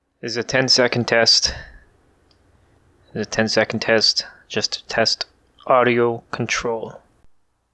this is a little dialogue i just put up just to test sound quality.
Now it passes just that its loud and i can hear a bit of backgroud feed.
this is with noise reduction applied.
As you noted, it makes you sound dense and squashed. It brings up the background noise more each pass, and it’s easy to fall out of compression range by accident.